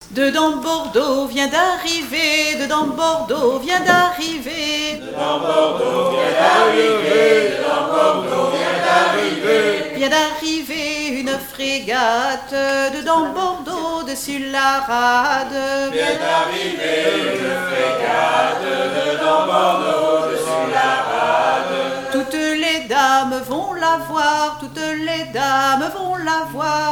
7e festival du chant traditionnel : Collectif-veillée
Pièce musicale inédite